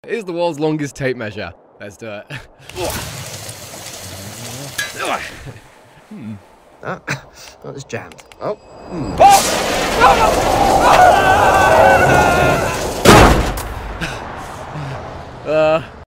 World Longest Tape Measure Sound Button - Free Download & Play
Reactions Soundboard1,394 views